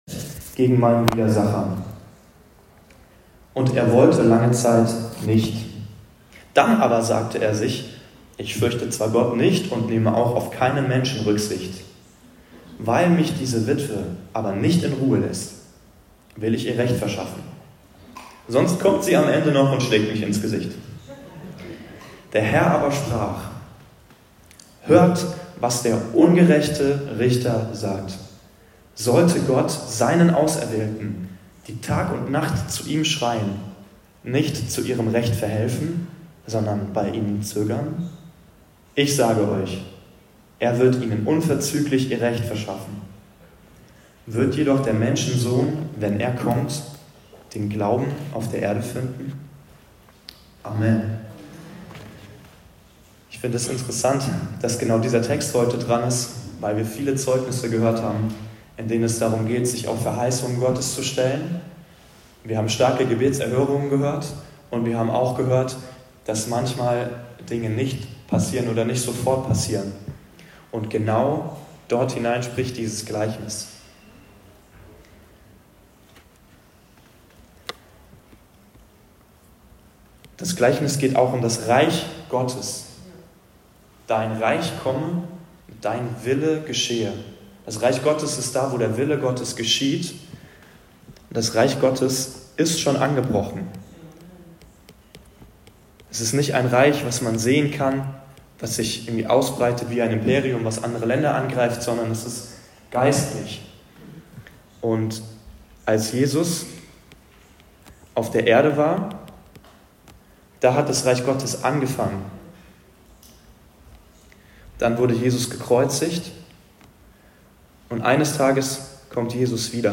Das Gleichnis vom Richter und der Witwe ~ Anskar-Kirche Hamburg- Predigten Podcast